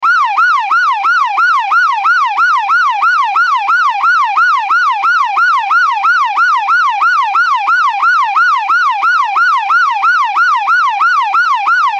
Police Siren Wail Sound Effect
Description: Police siren wail sound effect. Loud, loopable isolated siren sound from a police car, ambulance, fire truck, or other emergency vehicle with rotating effect.
Police-siren-wail-sound-effect.mp3